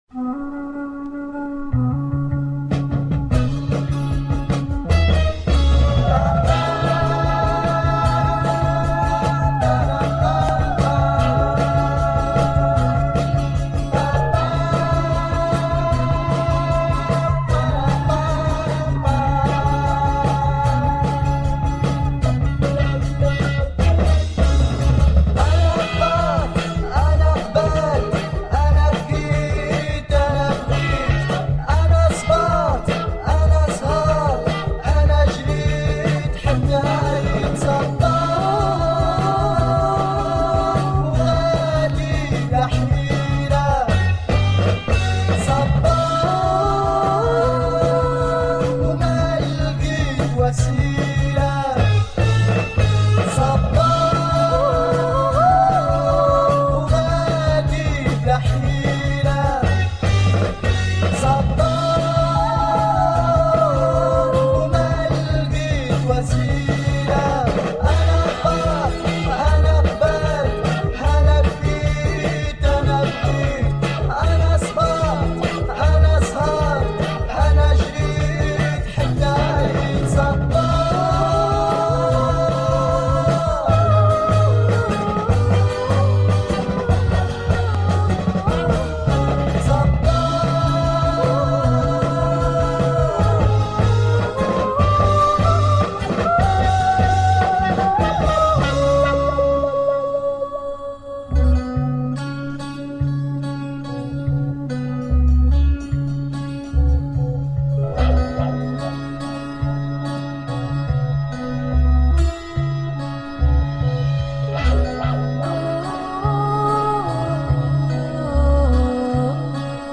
oriental psych